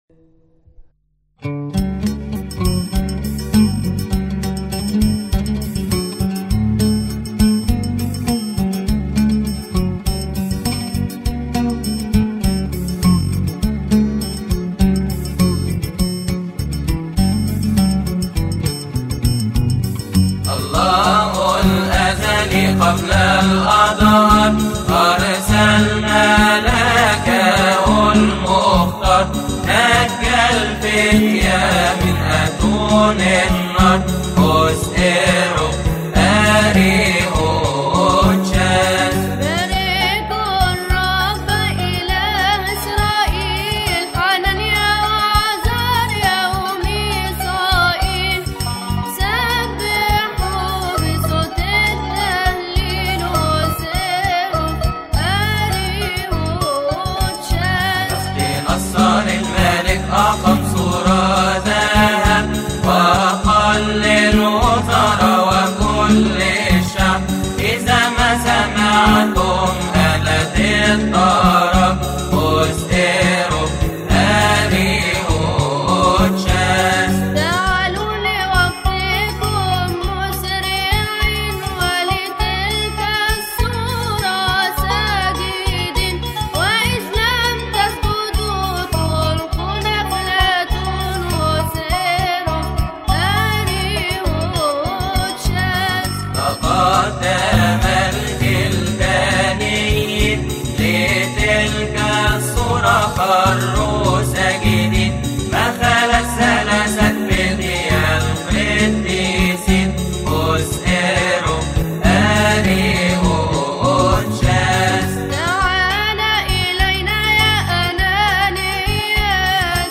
• المصدر : فريق ابو فام الجندي
مديح بعد ابصالية الثلاثة فتية يقال في تسبحة نصف الليل بشهر كيهك فريق ابو فام الجندي، عربي